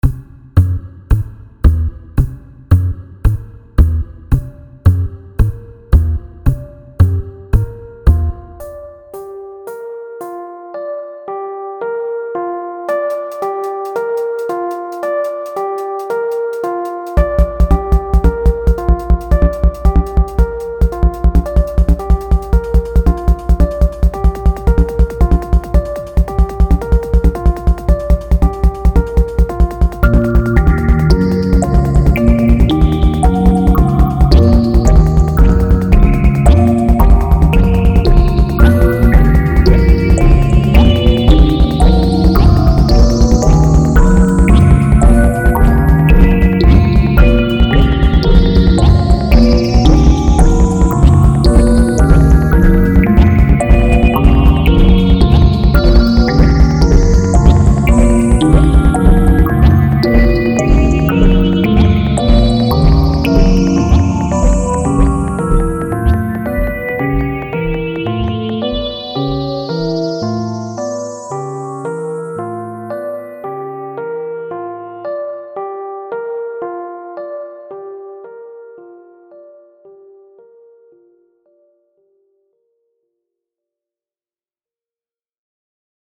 Video Game